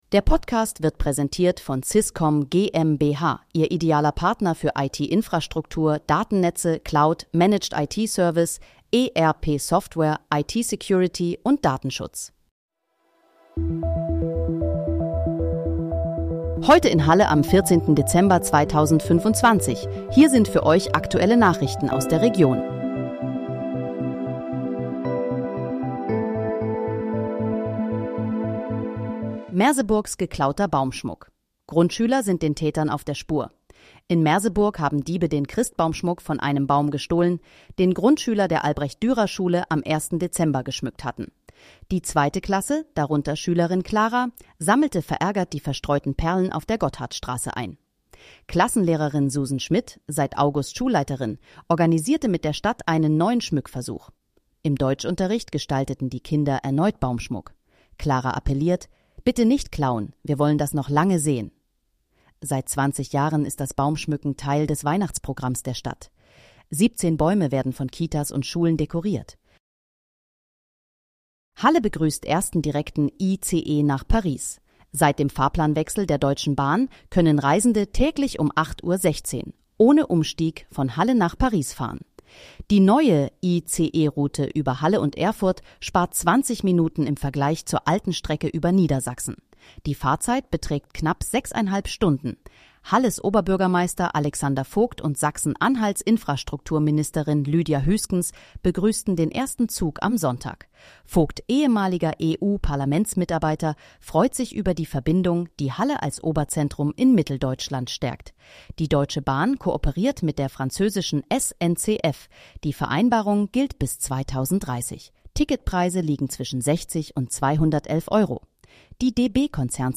Heute in, Halle: Aktuelle Nachrichten vom 14.12.2025, erstellt mit KI-Unterstützung
Nachrichten